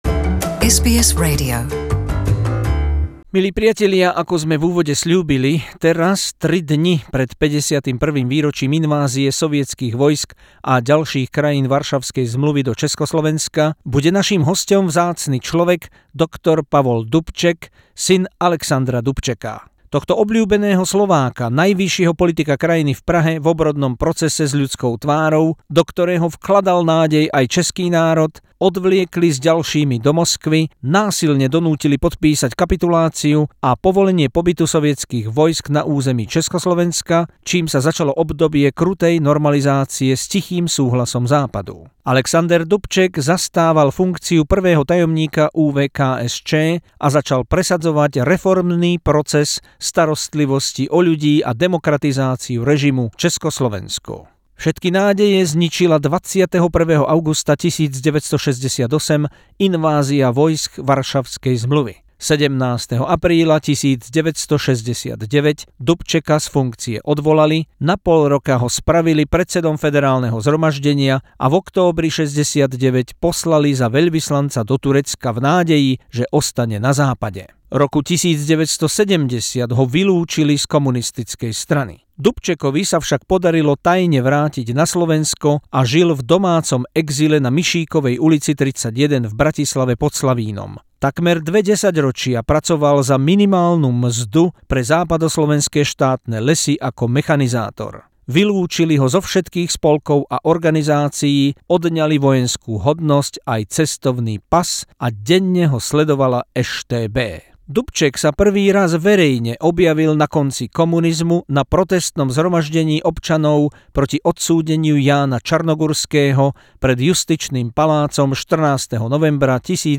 Exclusive SBS interview